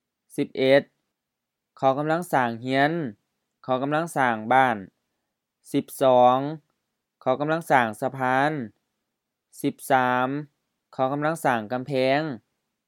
IsaanPronunciationTonesThaiEnglish/Notes